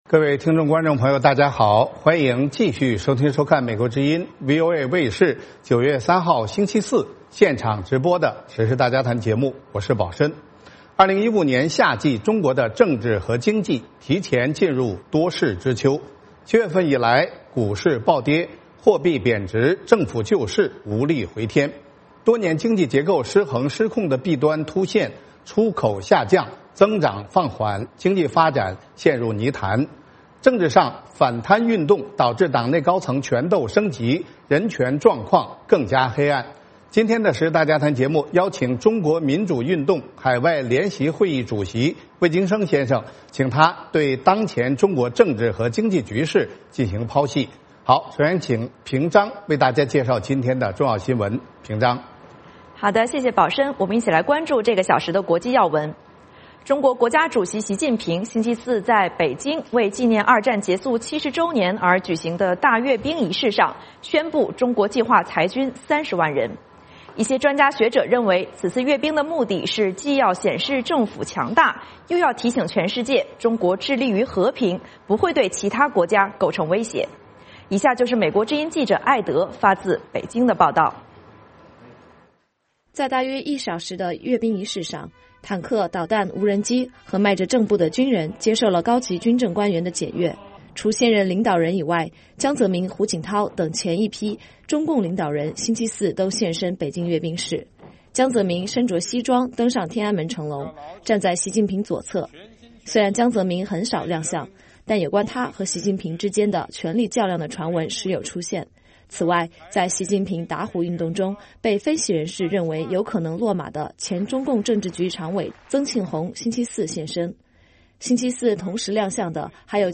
VOA卫视第二小时内容包括重要国际新闻以及时事大家谈。《时事大家谈》围绕重大事件、热点问题、区域冲突以及中国内政外交的重要方面，邀请专家和听众、观众进行现场对话和讨论，利用这个平台自由交换看法，探索事实。